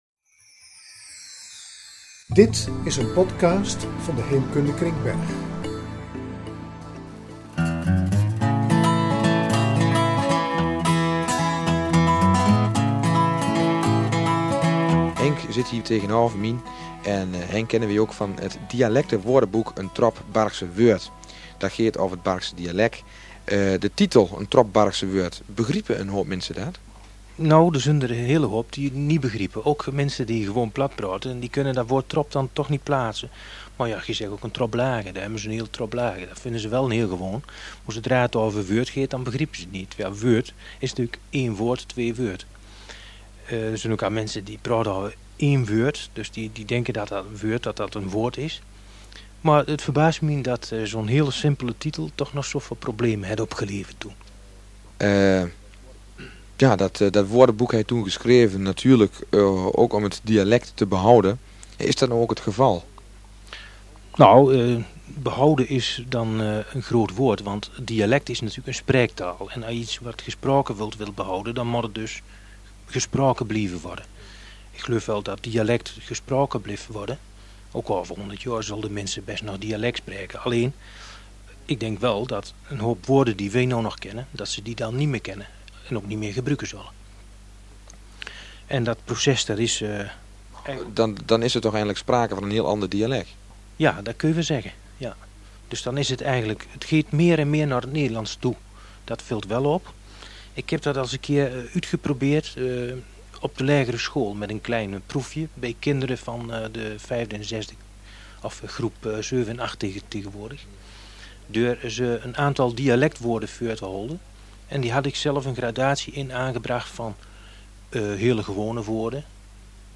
gedurende de jaren 1994 en 1995 en zun uutgezonde deur Optimaal FM in de jaore 1994 en 1995. De onderwarpe die wiere behandeld ware onder andere ’t Barghs dialec en reportages oaver plaatse in Bargh en Ummelanden en verhale uut de streek.